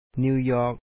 níw jɔ̄k New York